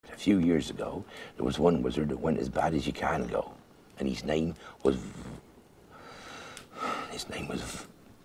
Гарри Поттер и Философский Камень — по фильму. На английском короткими фрагментами. 095-098